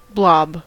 blob: Wikimedia Commons US English Pronunciations
En-us-blob.WAV